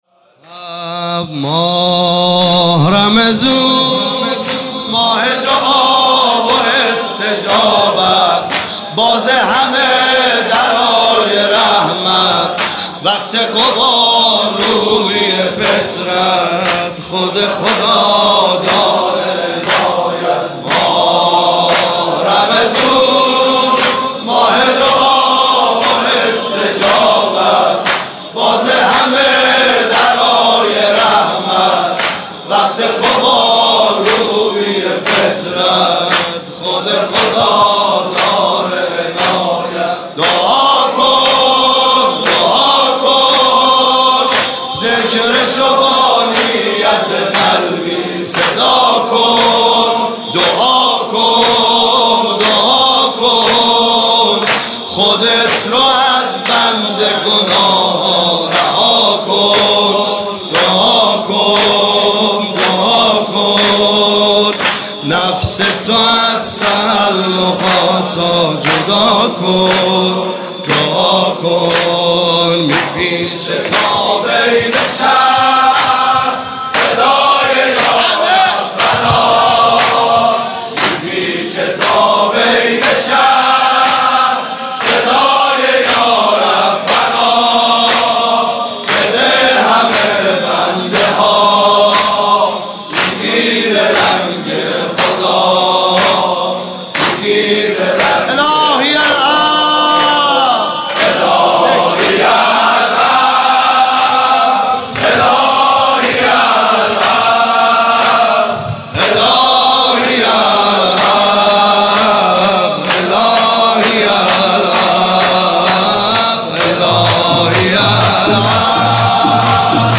عنوان شب ششم ماه مبارک رمضان ۱۳۹۸
مداح